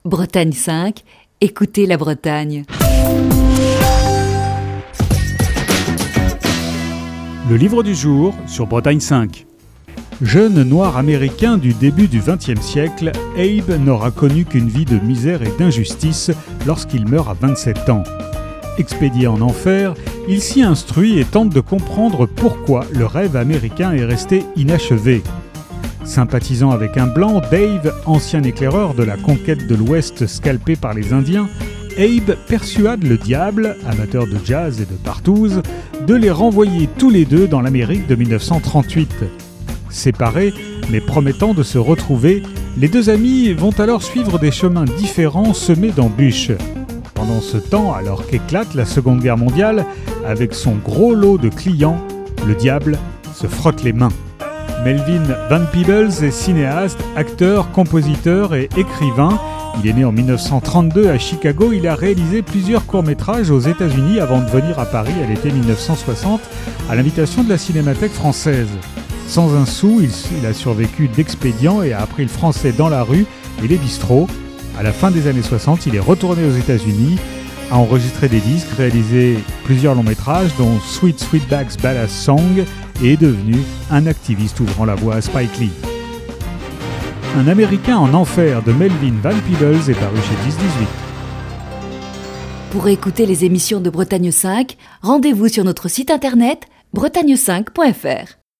Chronique du 19 mai 2021.